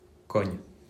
Cogne (French: [kɔɲ]
Fr-Cogne.mp3